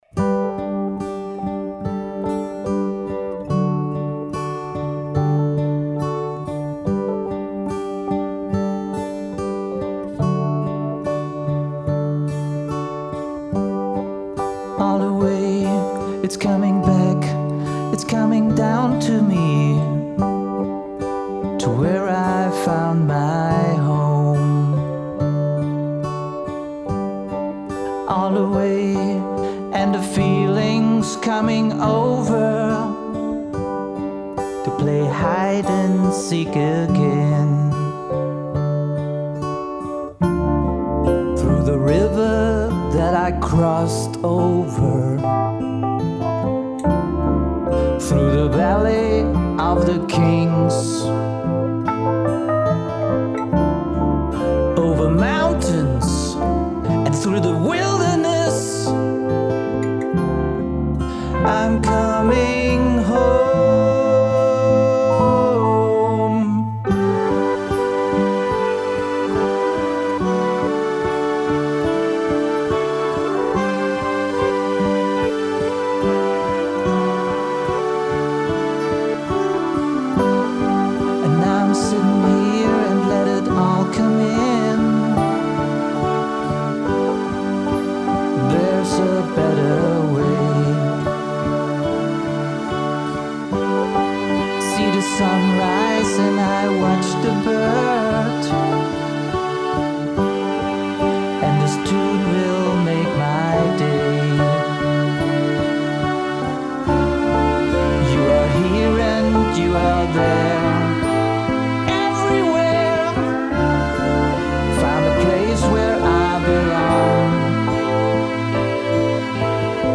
70er Ballade